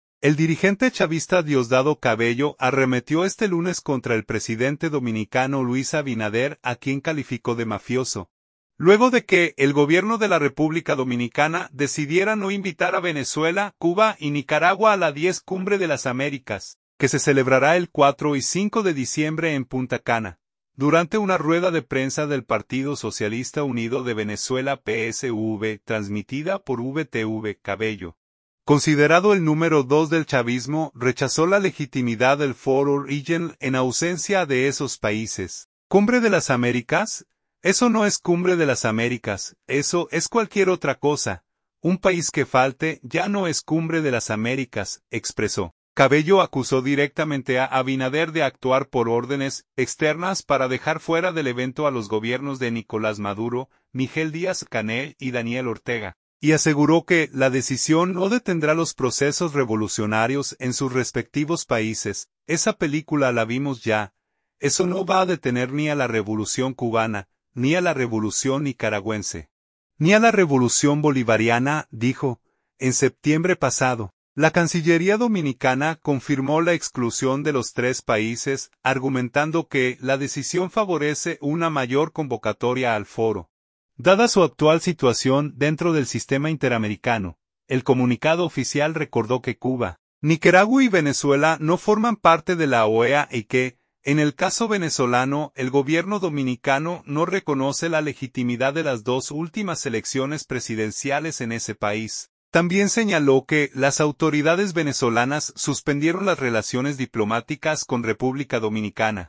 Durante una rueda de prensa del Partido Socialista Unido de Venezuela (PSUV), transmitida por VTV, Cabello, considerado el número dos del chavismo, rechazó la legitimidad del foro regional en ausencia de esos países.